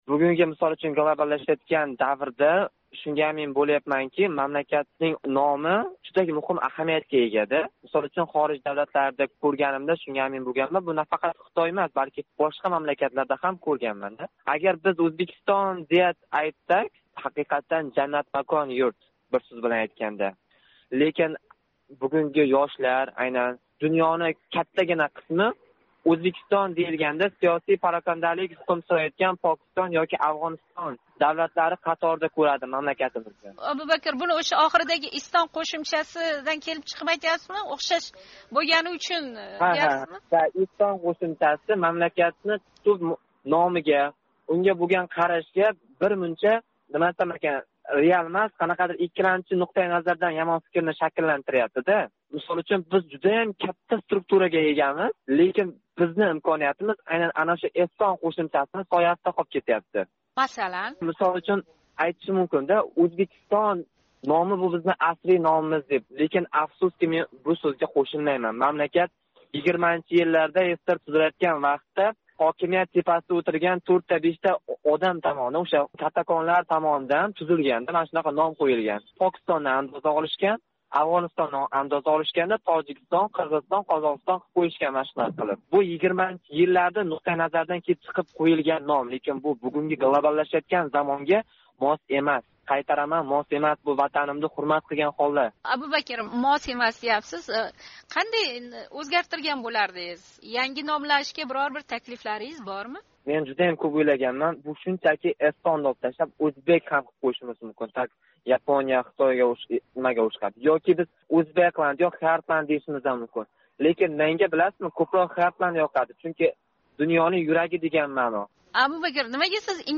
OzodМikrofonга боғланган талаба йигит -истон қўшимчаси ўзбек халқига мос эмас, деб ҳисоблайди.